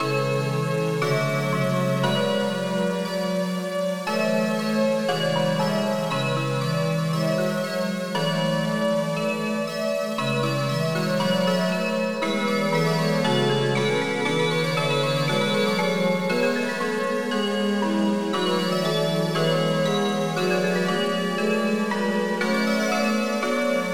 Synth Chorus + Crystal + New Age